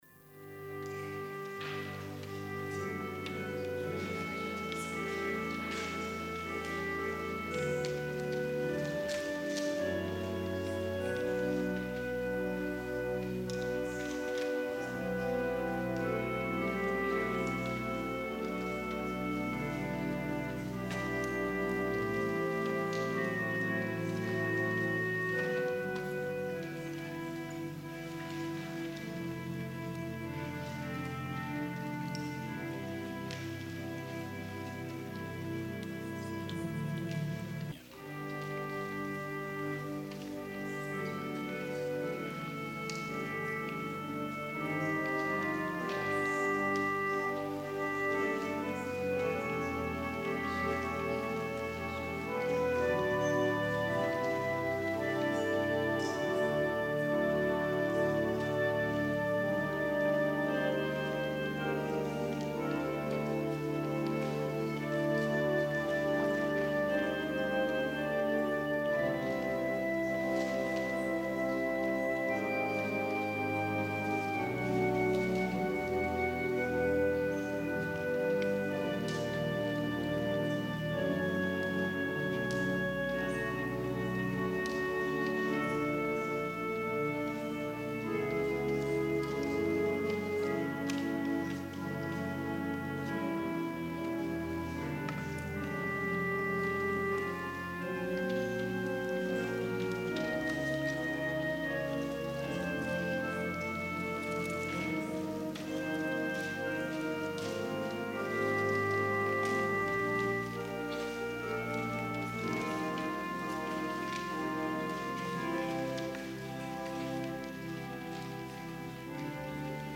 PRELUDE Aria
organ